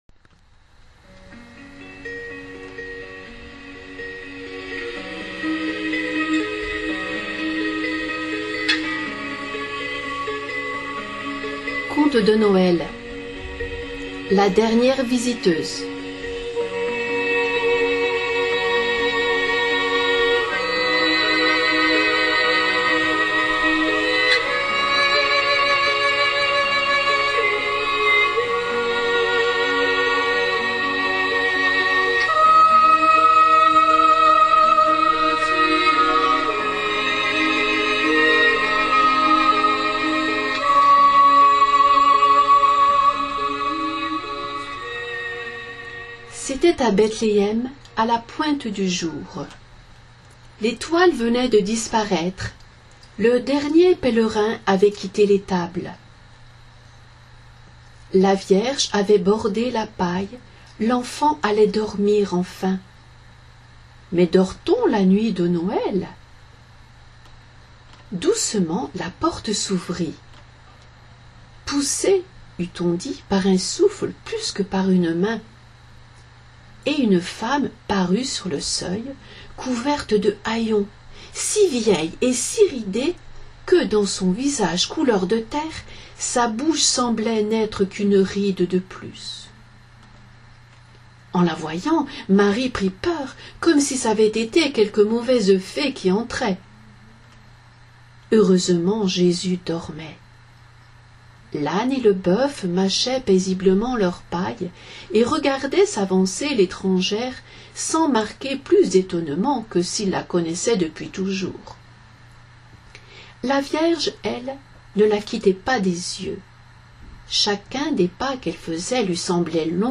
Histoires pour les enfants et pour ceux qui leur ressemblent >> Contes Histoires >> Contes >> Contes de Noel >> La dernière visiteuse Télécharger Pour partager :